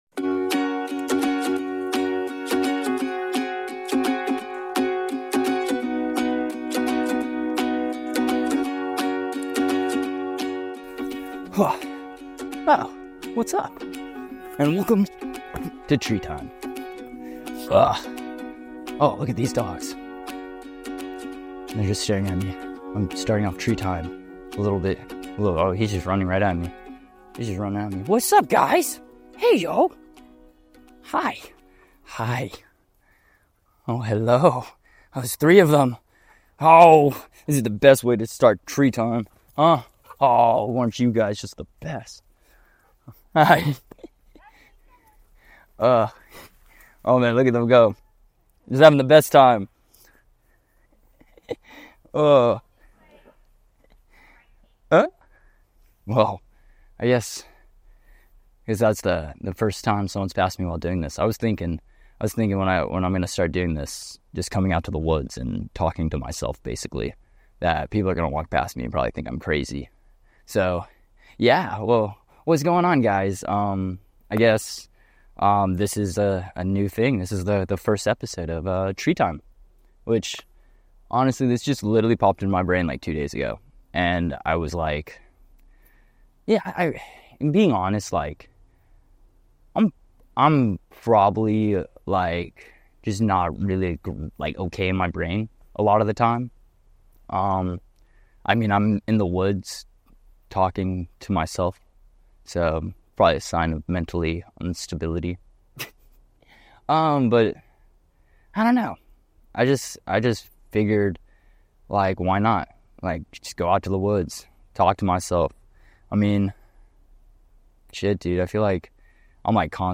Join me in my Tree Time and escape the world for 30 mistunes. As you listen to me talk to myself you'll feel all your worries wash away and realize you're probably not as crazy as you thought you were.